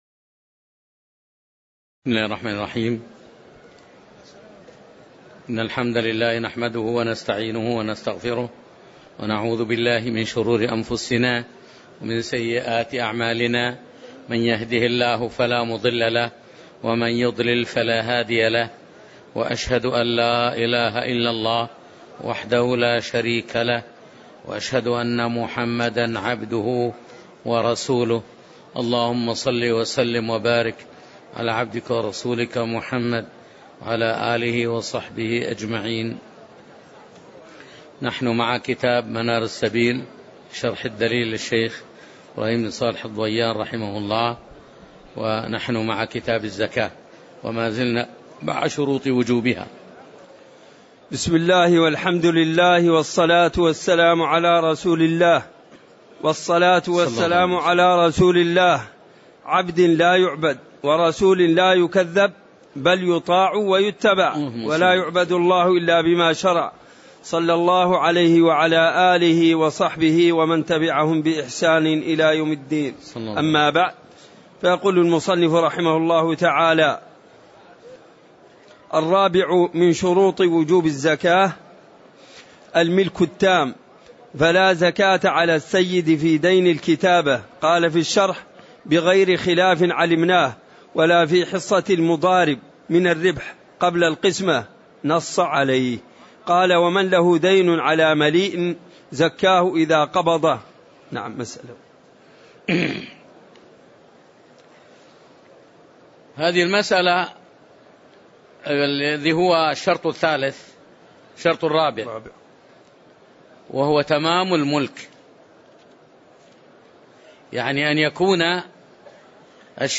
تاريخ النشر ١٤ جمادى الأولى ١٤٣٩ هـ المكان: المسجد النبوي الشيخ